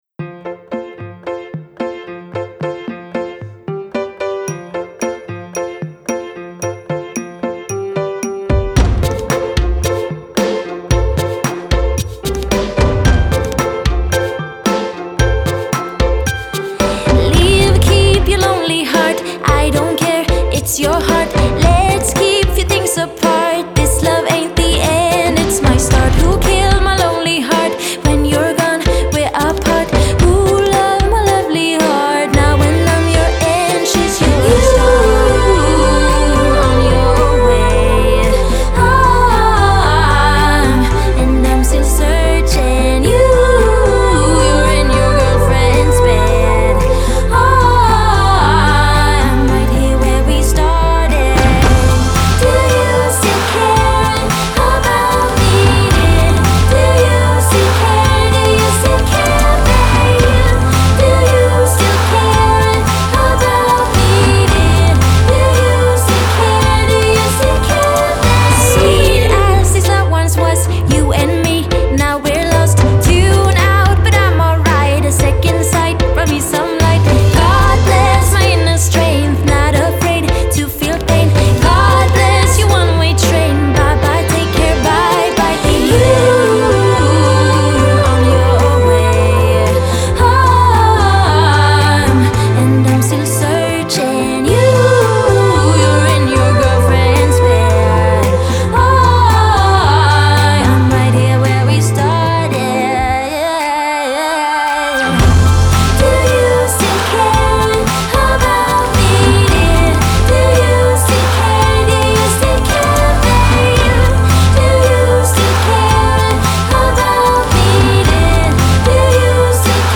pop
piano-laced pop track
breezy but powerful vocals